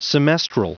Prononciation audio / Fichier audio de SEMESTRAL en anglais
Prononciation du mot : semestral